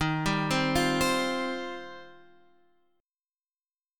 D#6add9 chord